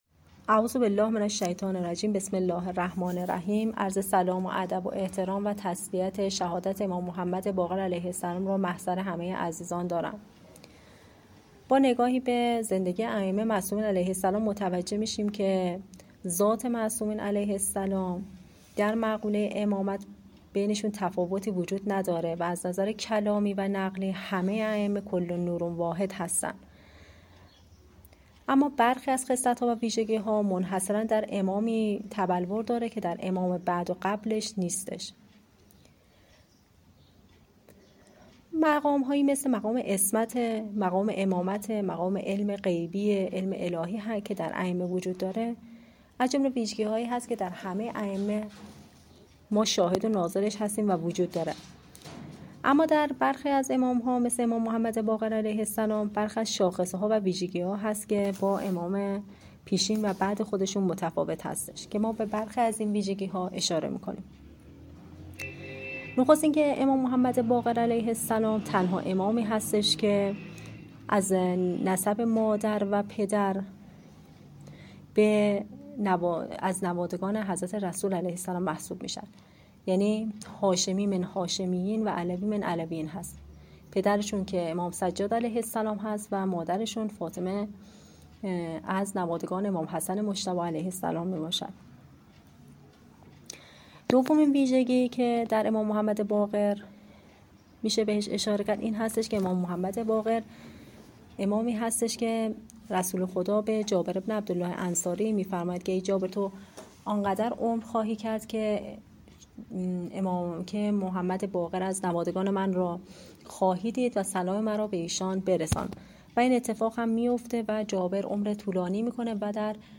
اراک در گفت و گو با خبرنگار خبرگزاری حوزه در اراک،اظهار داشت:اگرچه از نظرکلامی و نقلی همه ائمه کل نور واحد هستند و در آنچه مربوط به امامت می شوند تفاوتی بین ذات معصومین